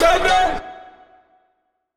TS Vox_13.wav